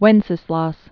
(wĕnsĭ-slôs), Saint c. 907-935?